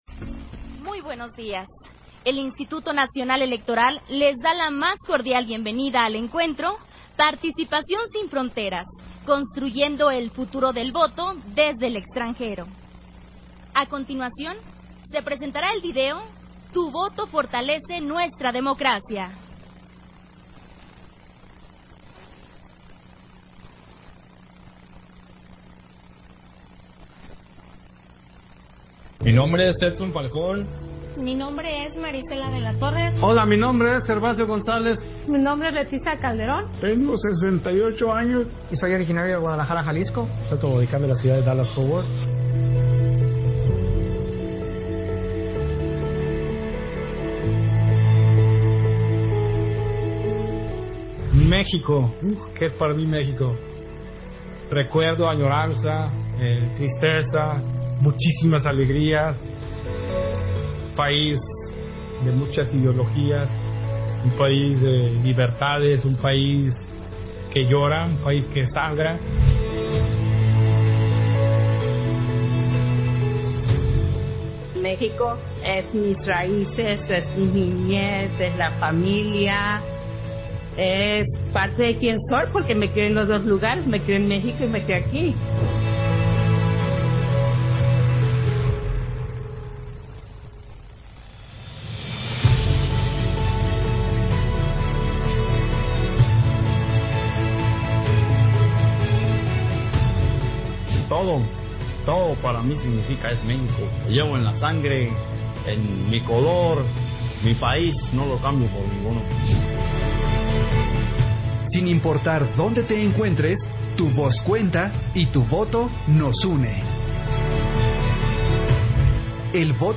Audio de la inauguración del Encuentro de Participación sin Fronteras: Construyendo el futuro del voto desde el extranjero